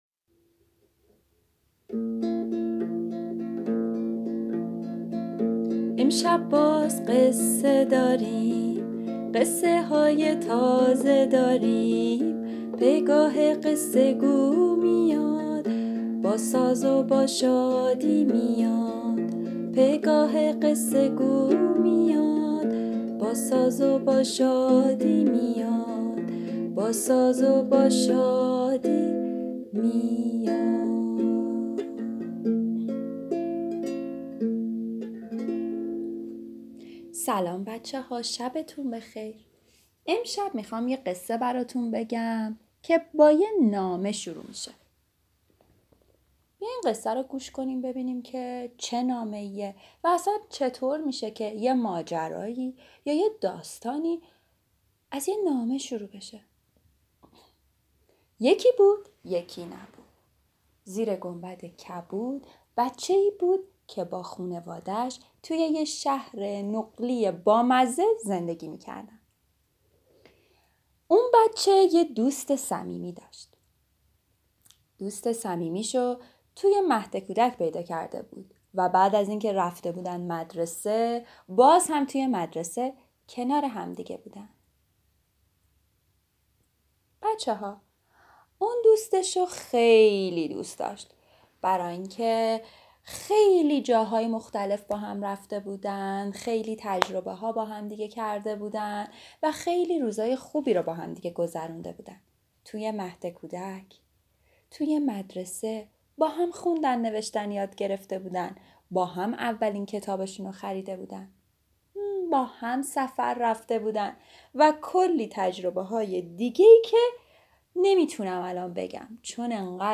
قصه صوتی کودکان دیدگاه شما 757 بازدید